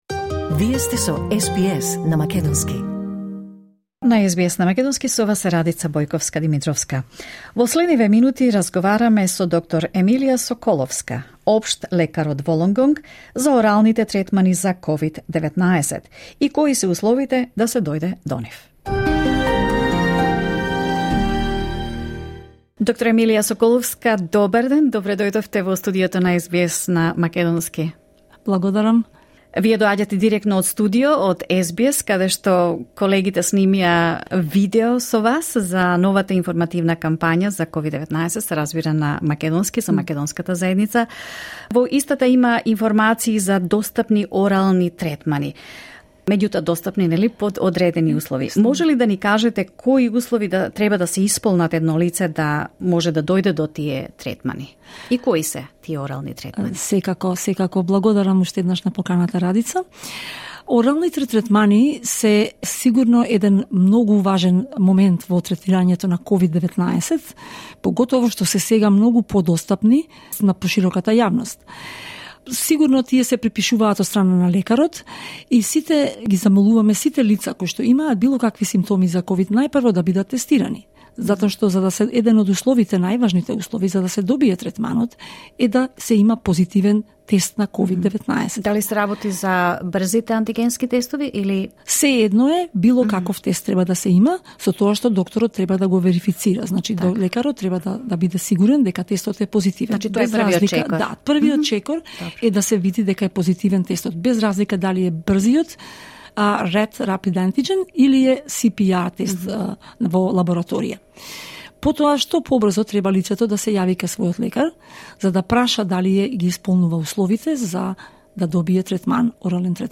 во студио на СБС Радио